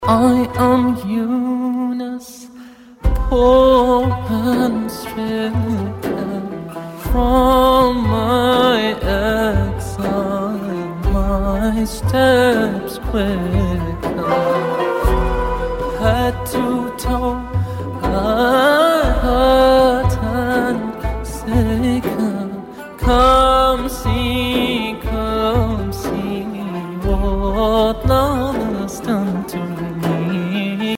آهنگ موبایل عاشقانه و محزون(با کلام)